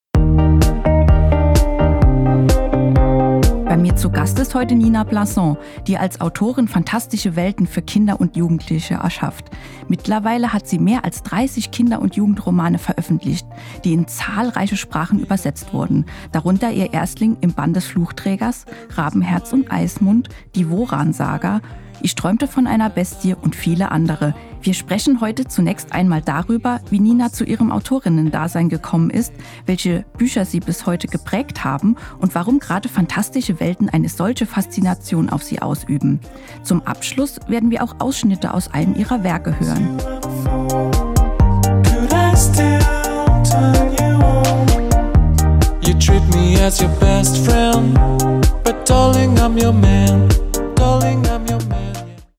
Moderation
Studiogast
Teaser_603.mp3